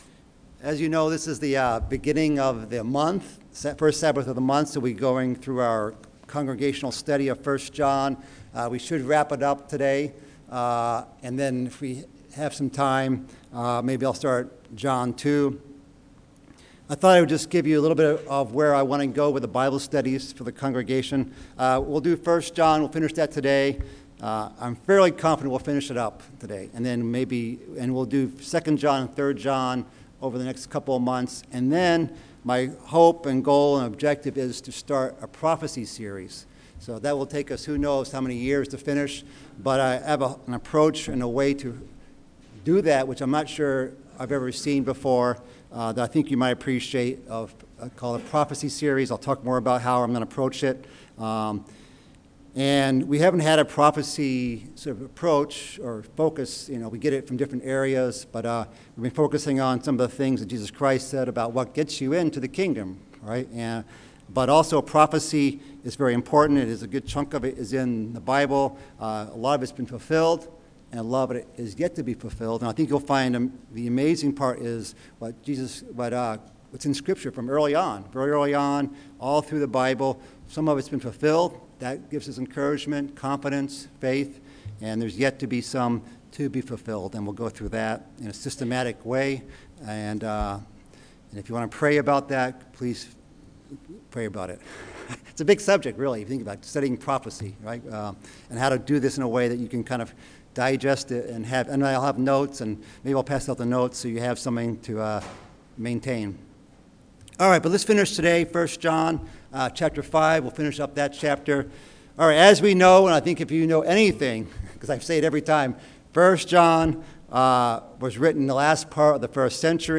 Bible Study: 1 John